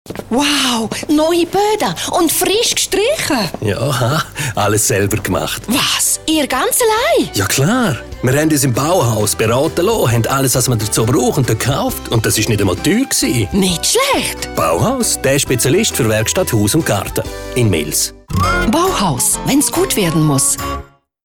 Werbung Schweizerdeutsch (GR)
Sprecherin mit breitem Einsatzspektrum.